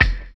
NY 2 BD.wav